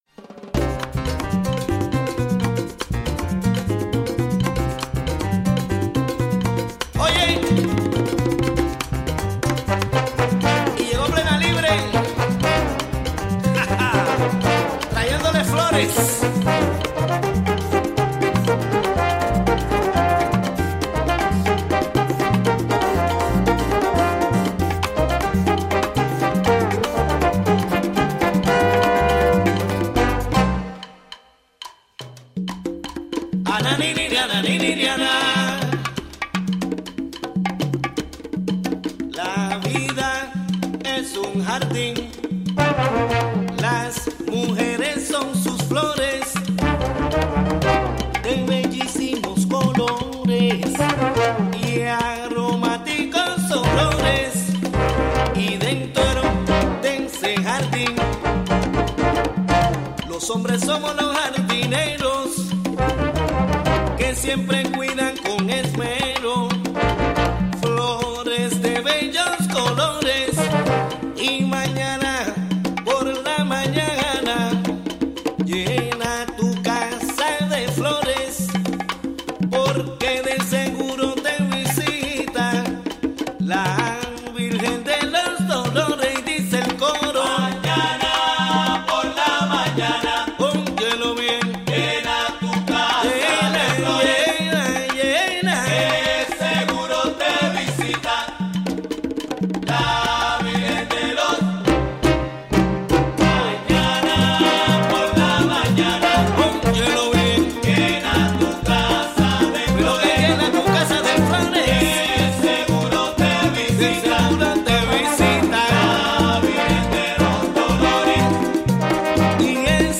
Weekly Spanish language radio news show
featuring interviews, commentary, calendar of events and music.